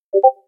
Звуки Discord
Пинг (уведомление), сообщение